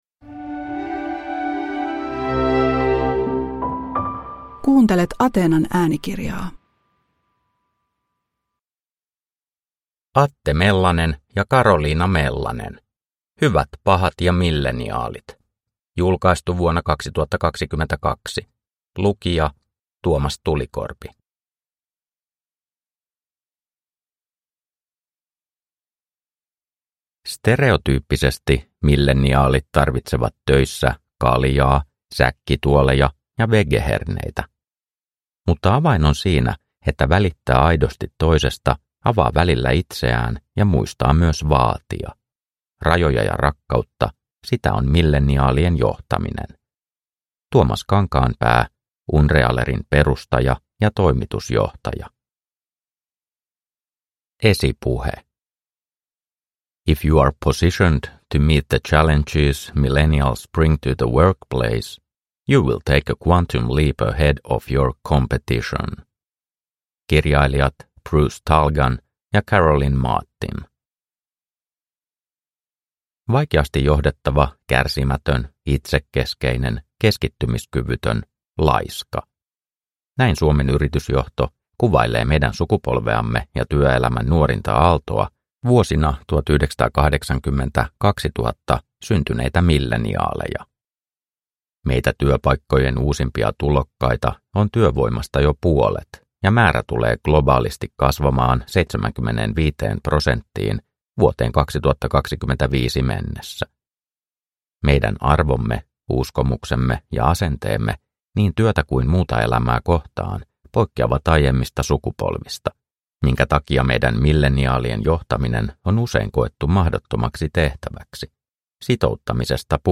Hyvät, pahat ja millenniaalit – Ljudbok – Laddas ner